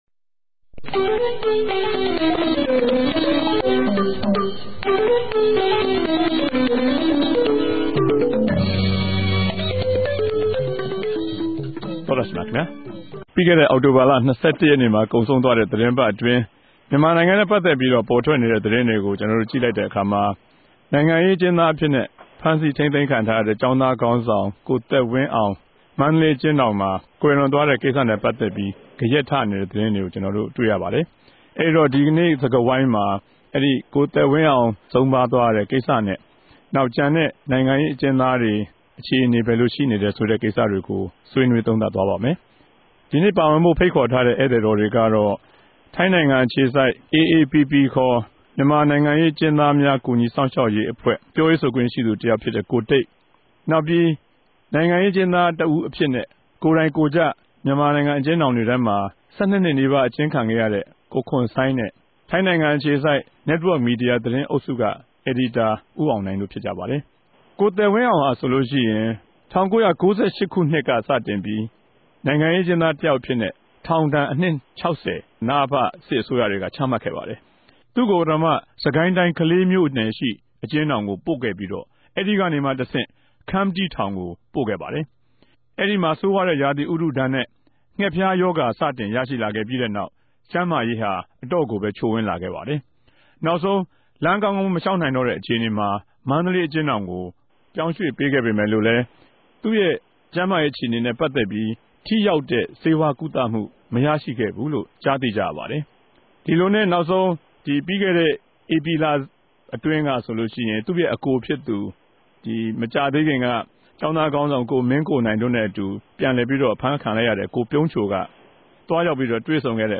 တပတ်အတြင်းသတင်းသုံးသပ်ခဵက် စကားဝိုင်း (၂၀၀၆ အောက်တိုဘာလ ၂၁ရက်)
ဝၝရြင်တန်္ဘမိြႚတော် RFAစတူဒီယိုထဲကနေ